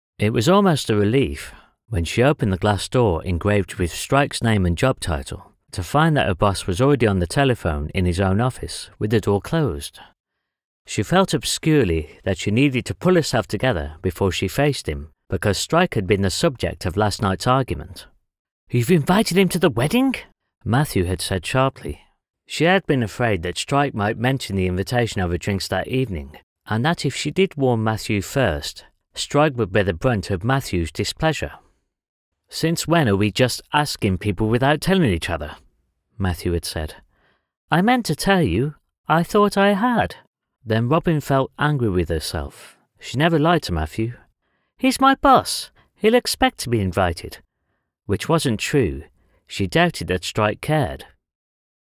Browse skilled audiobook narrators for every genre.
1106Nar-The_Cuckoos_Calling.mp3